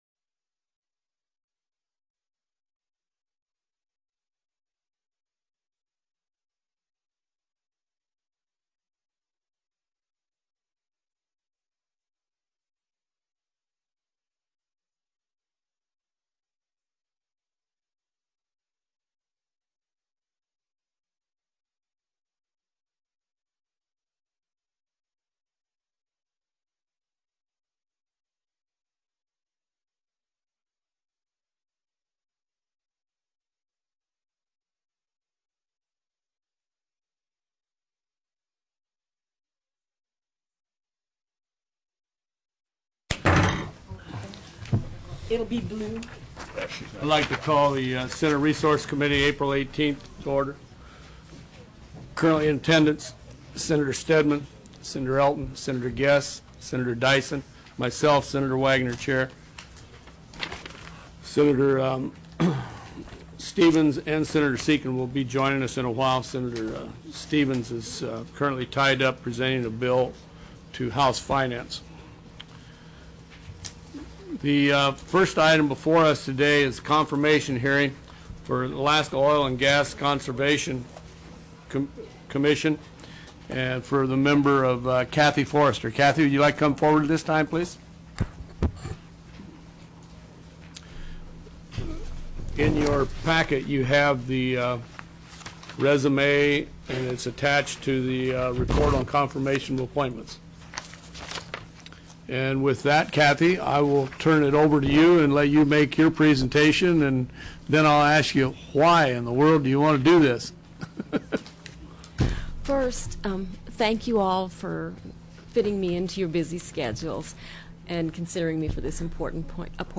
04/18/2005 03:30 PM Senate RESOURCES